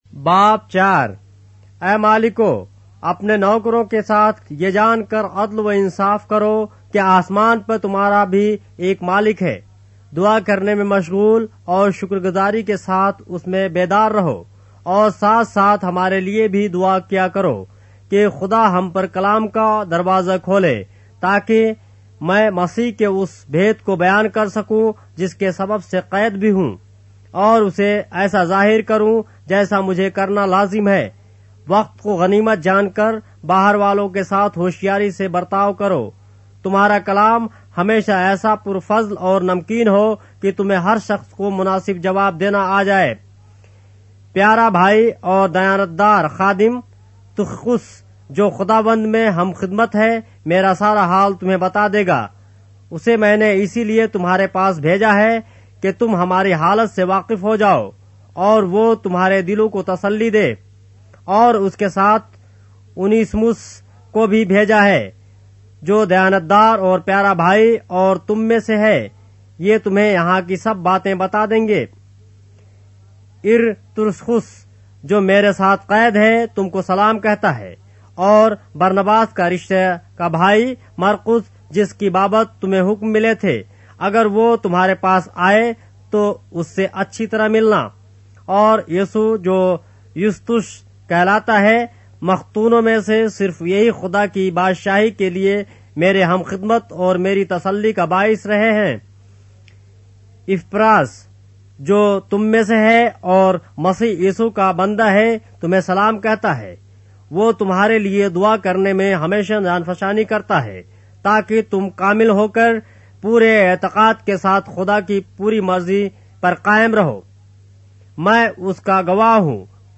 اردو بائبل کے باب - آڈیو روایت کے ساتھ - Colossians, chapter 4 of the Holy Bible in Urdu